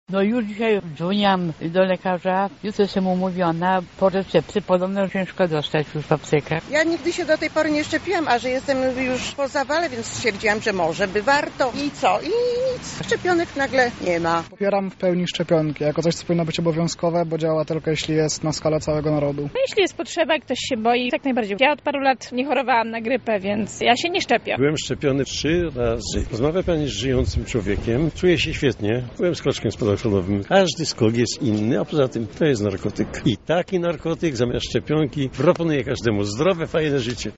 Zapytaliśmy mieszkańców Lublina, jakie mają zdanie na temat szczepienia się przeciwko grypie: